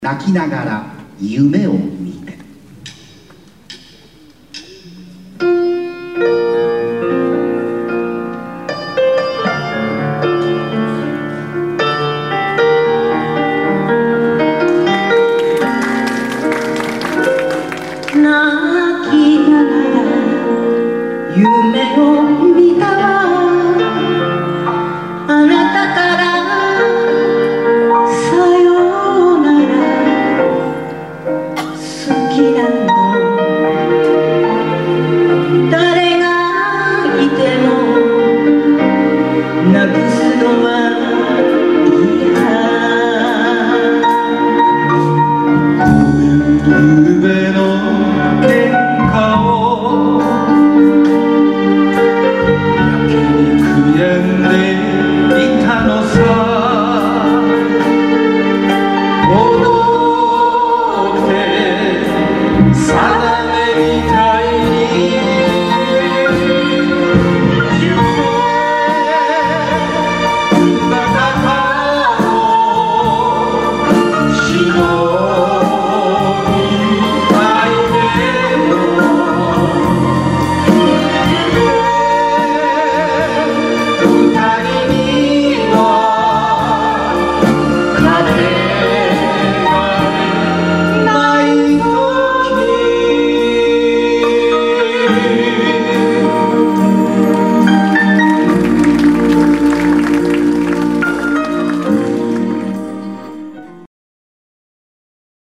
第１3回教室発表会